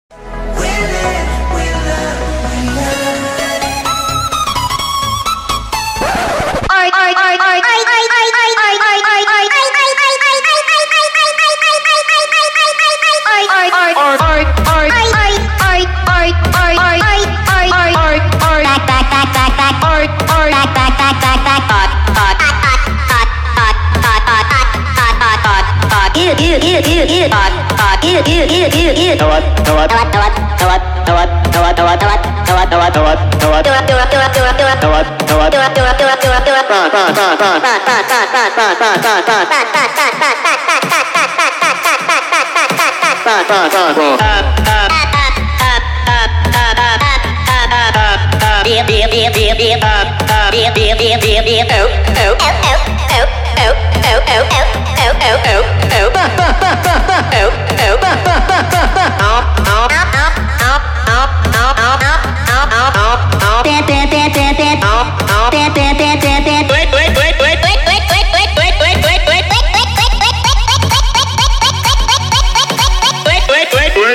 (COVER)